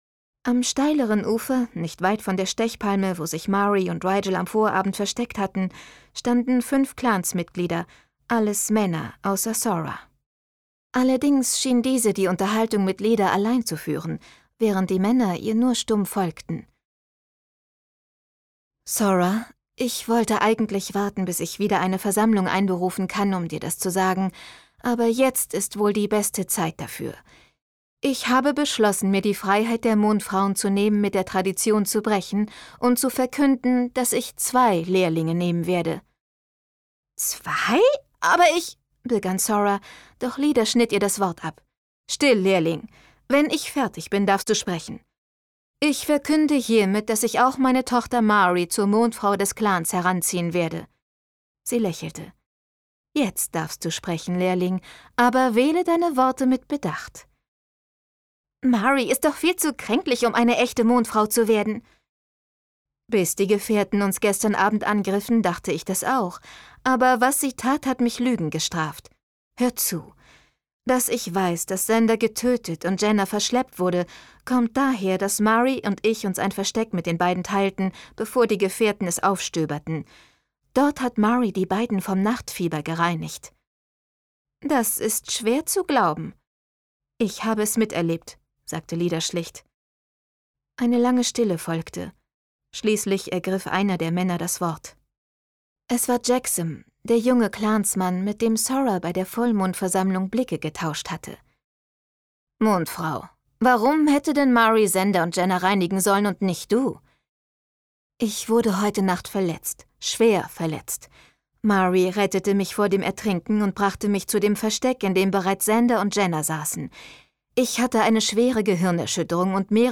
Marie Bierstedt (Sprecher)
Auch die House of Night-Serie hat Marie Bierstedt mit ihrer gefühlvollen Stimme bereits zum Leben erweckt.